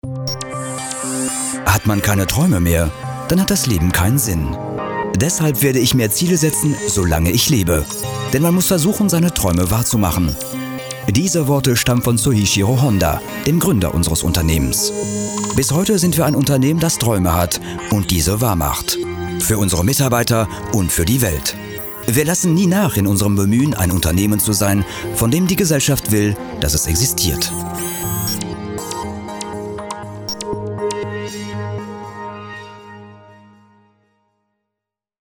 German male versatile professional voice over talent and actor - Fluent in French
Kein Dialekt
Sprechprobe: Industrie (Muttersprache):